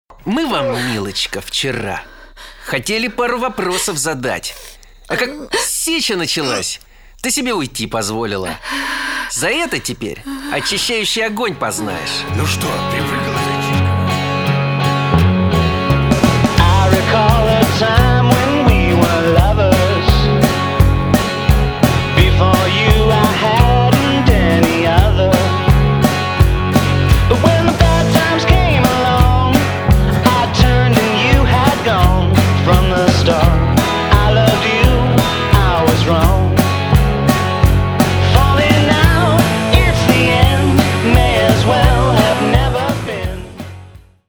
кантри